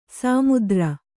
♪ sāmudra